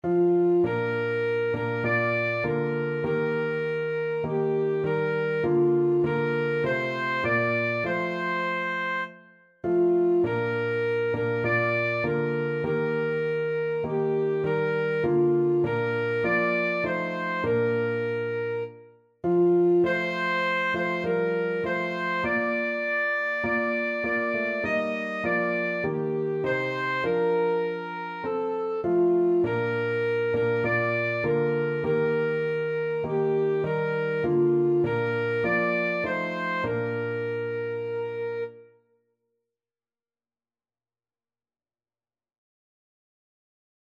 Alto Saxophone
4/4 (View more 4/4 Music)
Traditional (View more Traditional Saxophone Music)